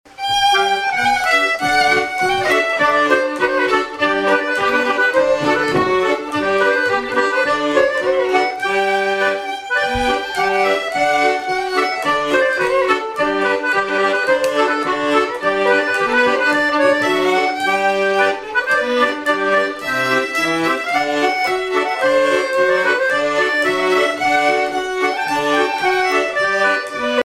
danse : quadrille : avant-quatre
Répertoire de bal au violon et accordéon
Pièce musicale inédite